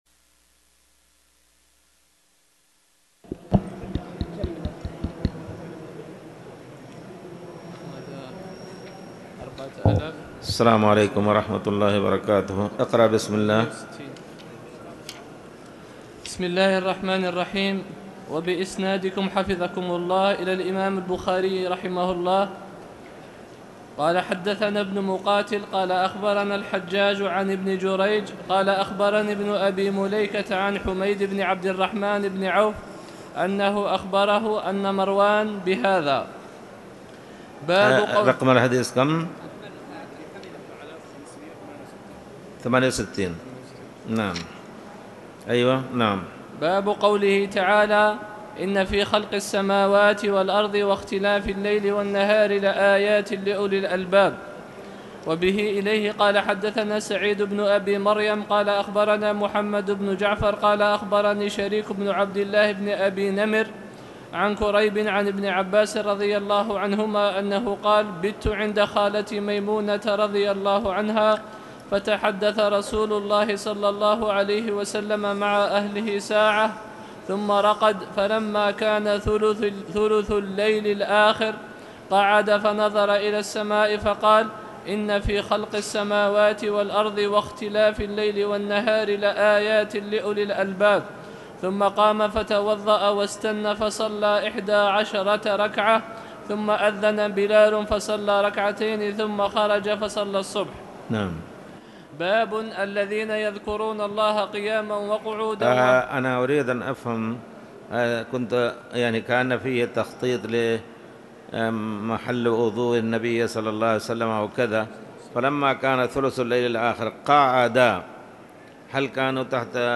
تاريخ النشر ٧ محرم ١٤٣٨ هـ المكان: المسجد الحرام الشيخ